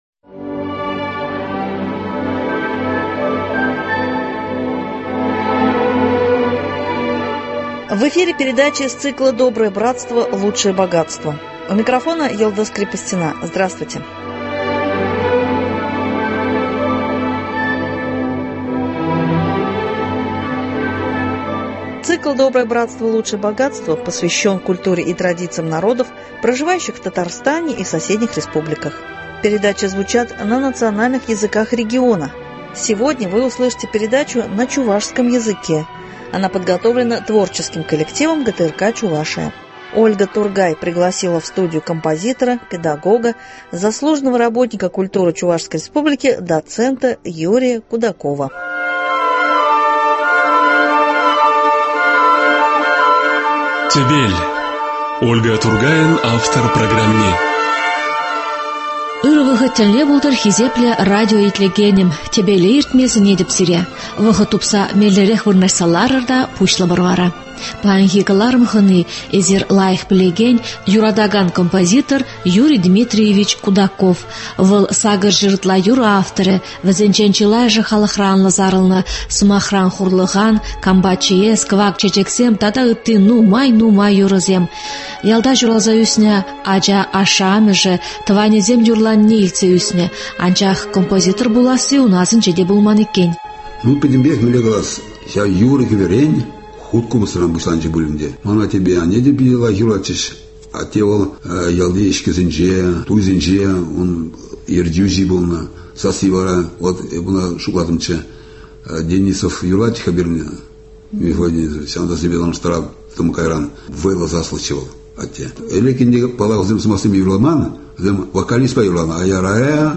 пригласила в студию композитора